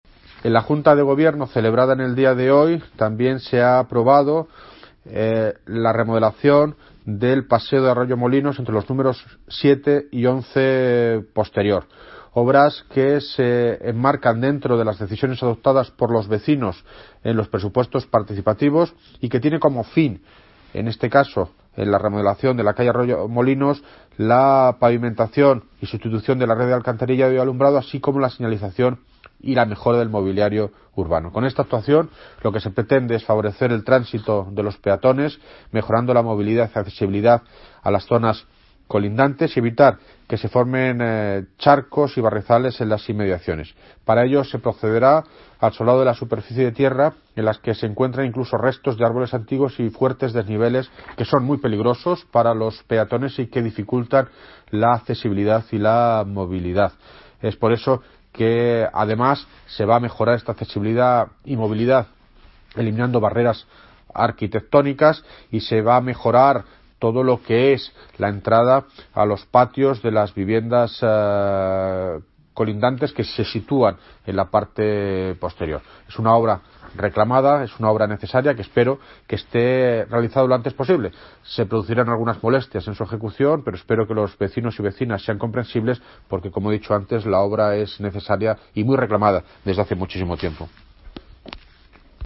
Audio - David Lucas (Alcalde de Móstoles) Sobre Remodelación del Paseo de Arroyomolinos